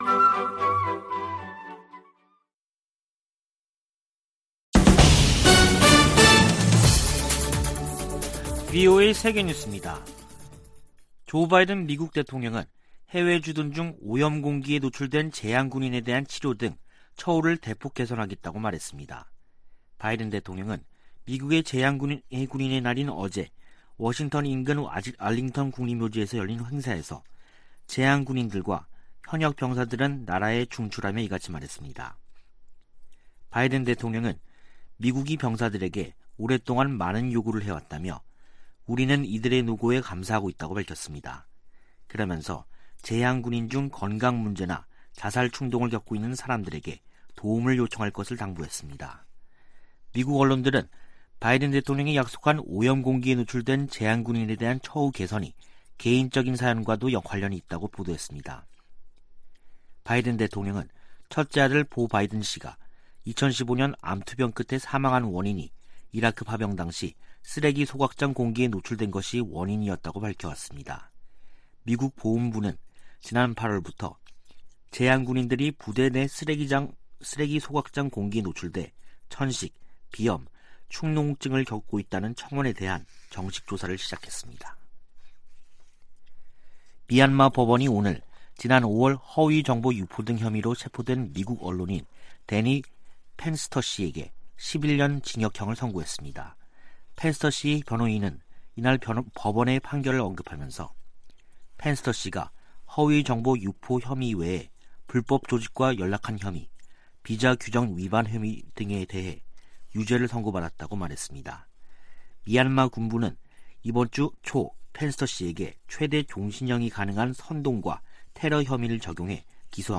VOA 한국어 간판 뉴스 프로그램 '뉴스 투데이', 2021년 11월 12일 2부 방송입니다. 미국과 종전선언의 큰 원칙에 합의했다는 한국 외교부 장관의 발언이 나오면서 실제 성사 여부에 관심이 쏠리고 있습니다. 북한이 핵실험을 유예하고 있지만 미사일 탐지 회피 역량 개발에 집중하고 있다고 미국 유력 신문이 보도했습니다. 미국 정부가 캄보디아와 연관된 미국 기업들에 대한 주의보를 발령하면서, 북한의 현지 불법 활동에 대해서도 주의를 당부했습니다.